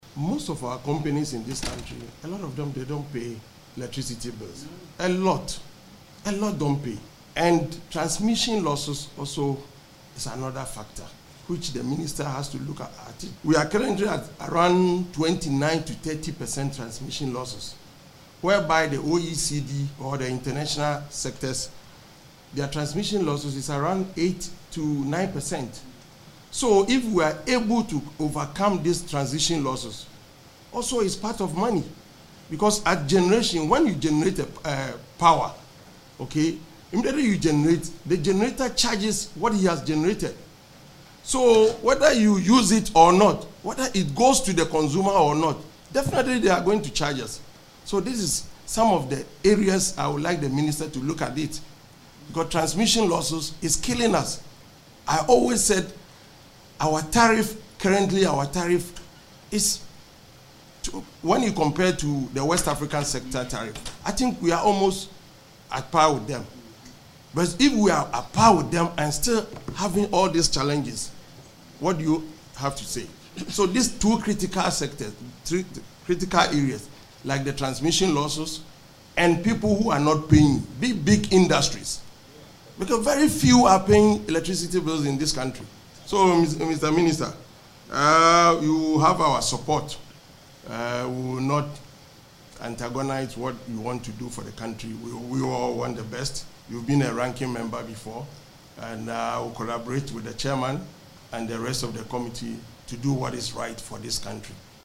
On his part, the Ranking Member on the Energy Committee, George Kwame Aboagye, criticised large companies for failing to pay their electricity bills.